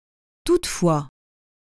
toutefois – [tutfwa]